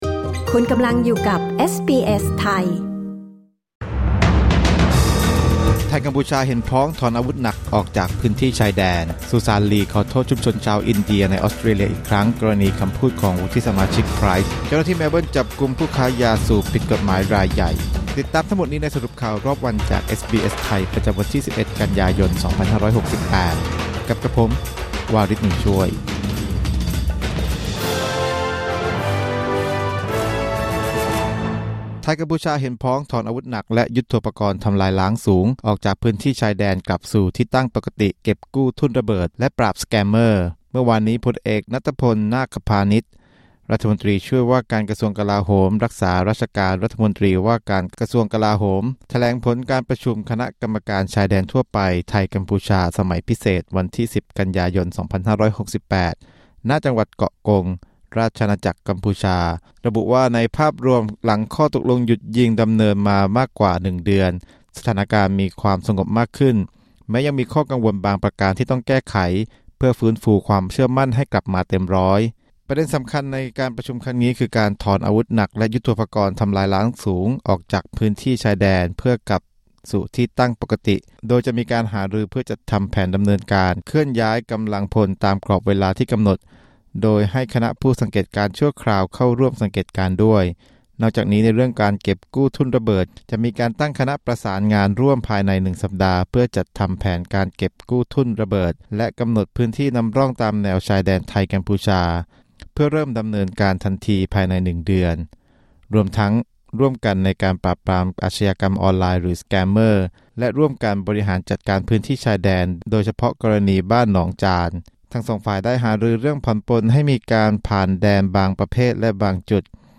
สรุปข่าวรอบวัน 11 กันยายน 2568